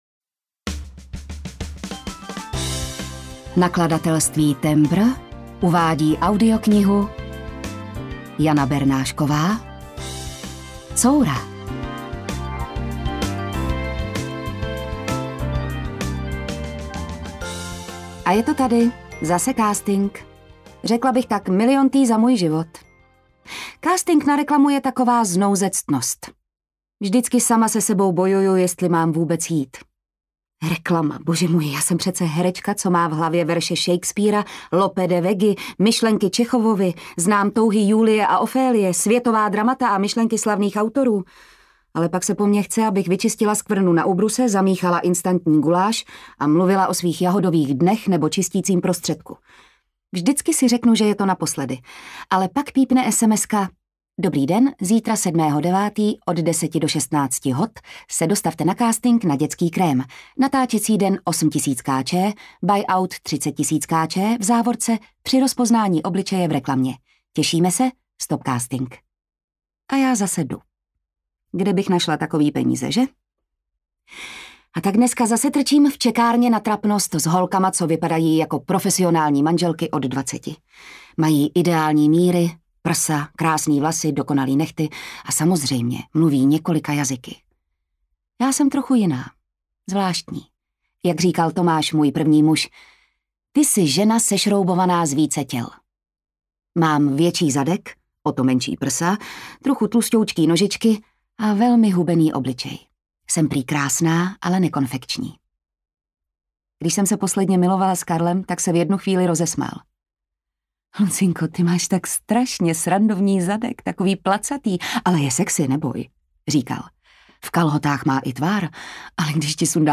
Coura audiokniha
Ukázka z knihy
• InterpretJitka Ježková